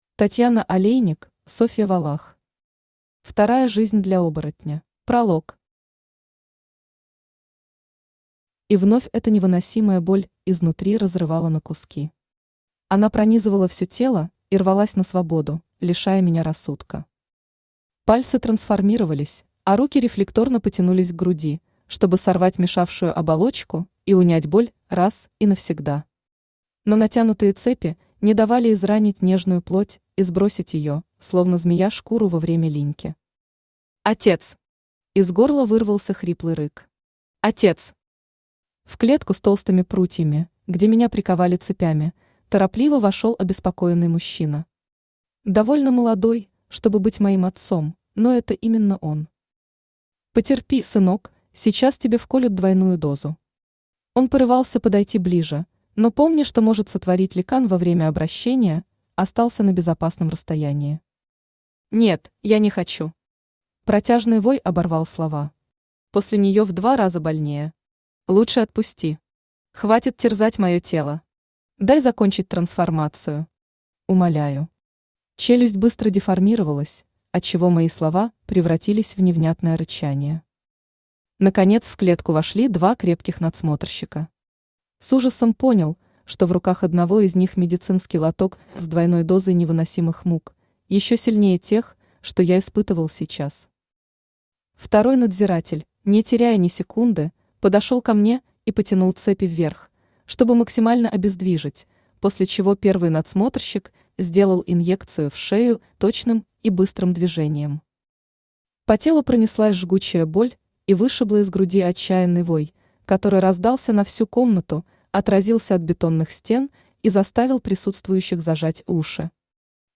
Aудиокнига Вторая жизнь для оборотня Автор Татьяна Олейник Читает аудиокнигу Авточтец ЛитРес.